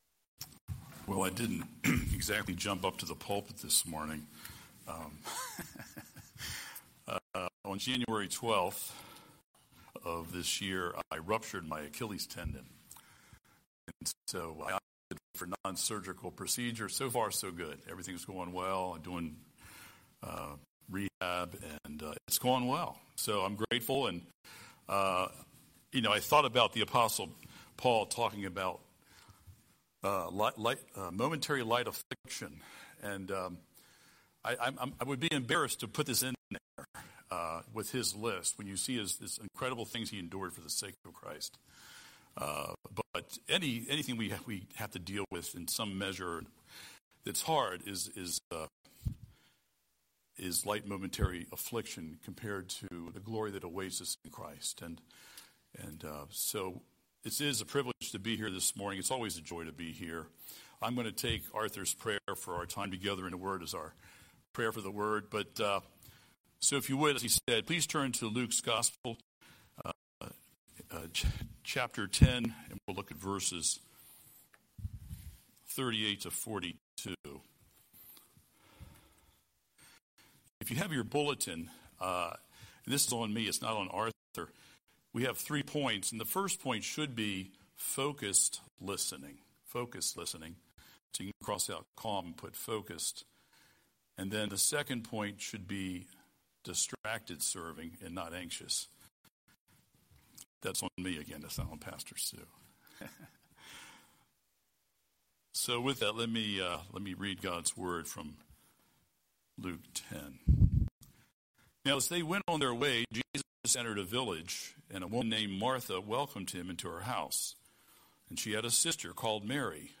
Scripture: Luke 10:38-42 Series: Sunday Sermon